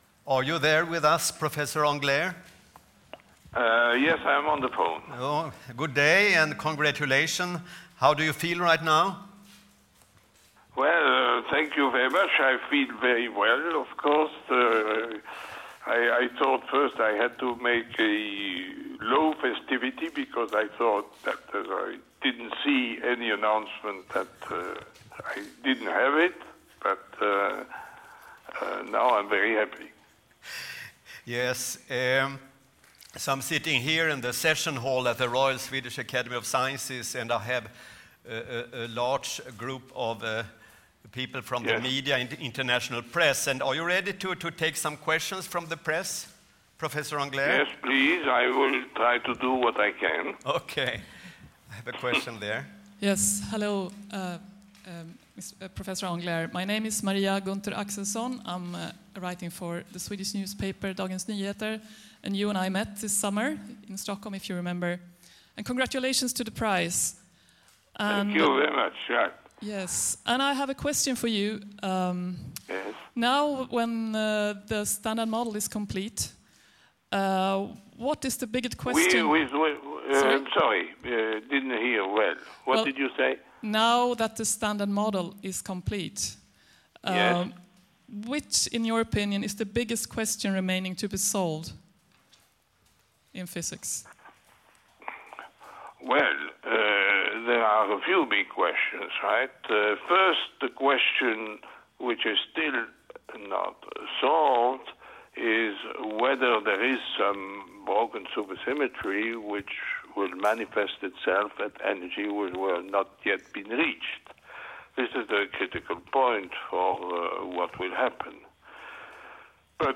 Telephone press interview, 8 October 2013
Telephone press interview with François Englert following the announcement of the 2013 Nobel Prize in Physics on 8 October 2013. The interviewer is Staffan Normark, Permanent Secretary of the Royal Swedish Academy of Sciences.
interview_2013_phy_englert_kva.mp3